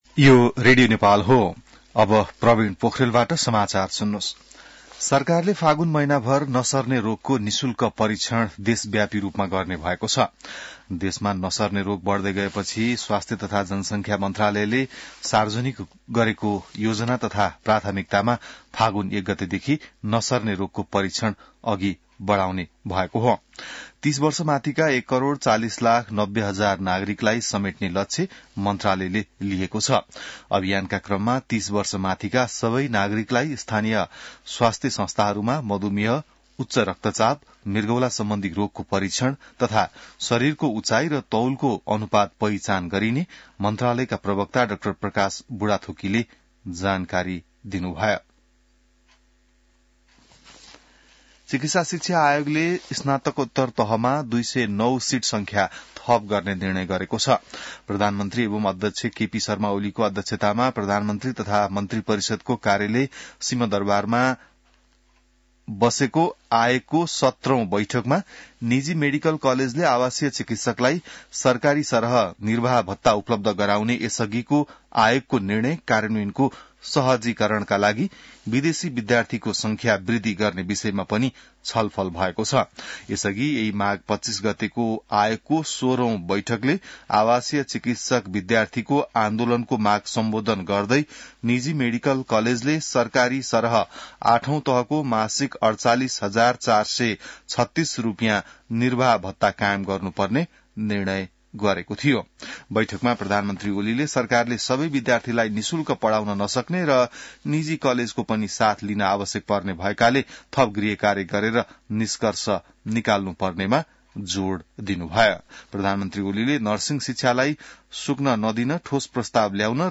बिहान ६ बजेको नेपाली समाचार : २ फागुन , २०८१